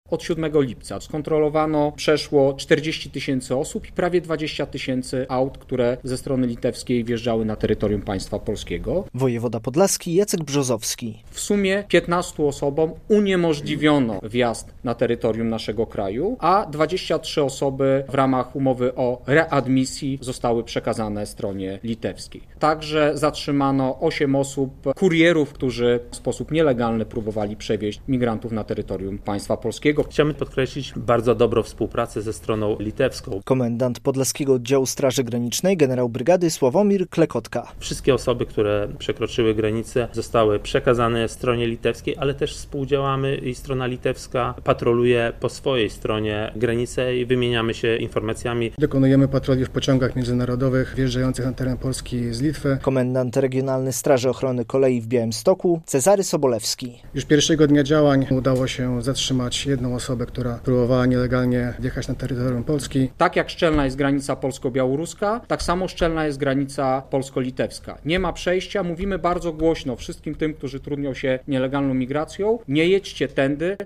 Podsumowanie tygodnia kontroli na granicy - relacja